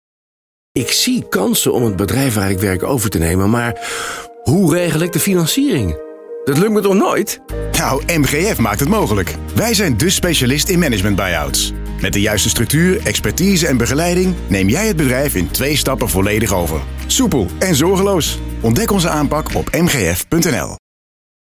Heb je ons al op BNR Nieuwsradio gehoord?
In twee spots van 20 seconden vertellen we waar we bij MGF voor staan: een management buy-out zonder zorgen.